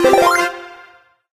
unlock_new_power_lvl_01.ogg